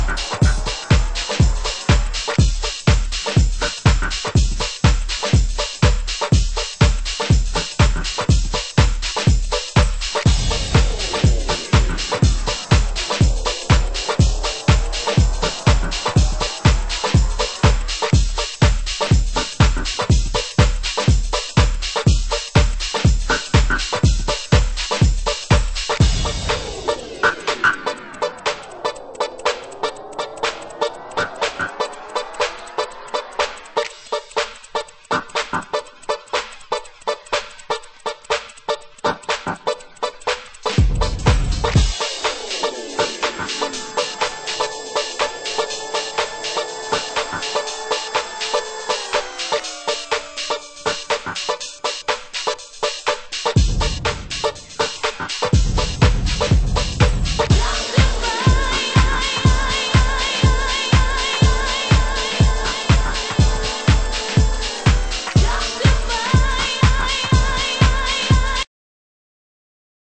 HOUSE MUSIC
Vocal Mix
(Epic Beats)   B1 (Instrumental)   B2 (Epic Dub) 　　盤質：良好